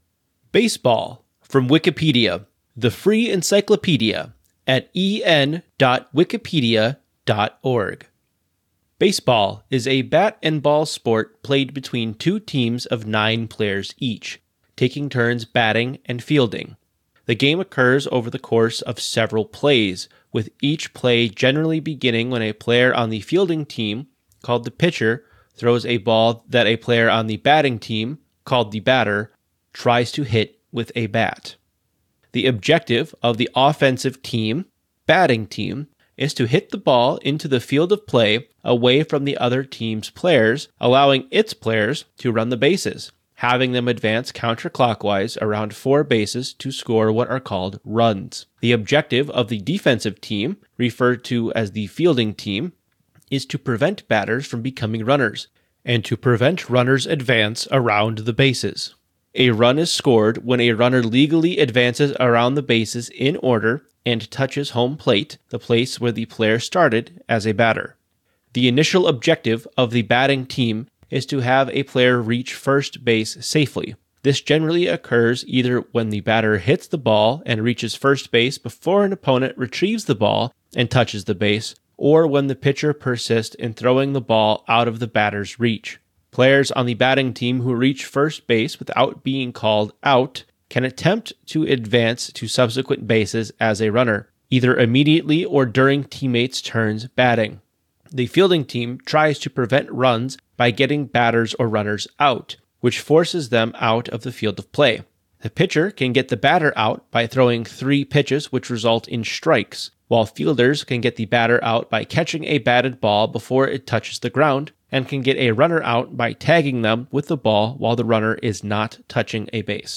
This is a spoken word version of the Wikipedia article: Baseball
Dialect/Accent InfoField Midwestern American English Gender of the speaker InfoField Male
Remastered for better audio quality
En-Baseball-article.ogg